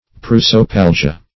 Search Result for " prosopalgia" : The Collaborative International Dictionary of English v.0.48: Prosopalgia \Pros`o*pal"gi*a\, n. [NL., fr. Gr.